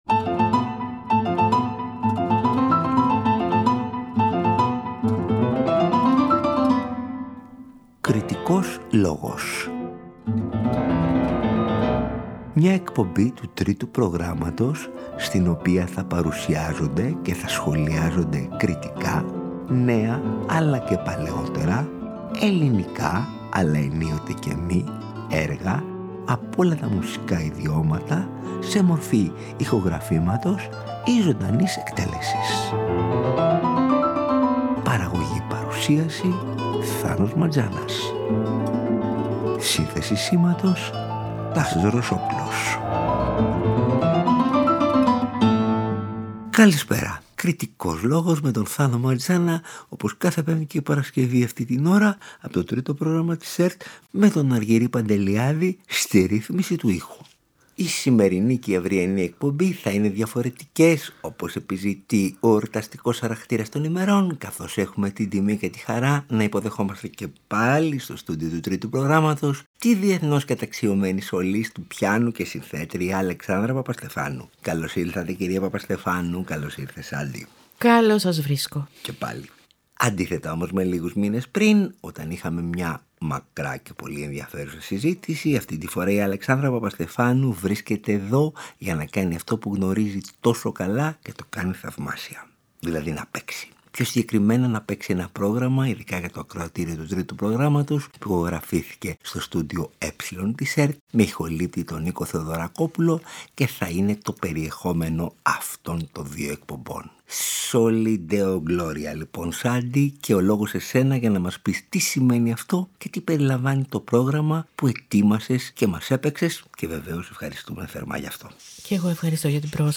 σε ένα χριστουγεννιάτικο ρεσιτάλ
Την διεθνώς καταξιωμένη σολίστ του πιάνου
έπαιξε και ηχογράφησε στο στούντιο Ε της ΕΡΤ
σε ρε μείζονα
σε σολ ελάσσονα
περικλείεται σε ένα υπέροχο εορταστικό ρεσιτάλ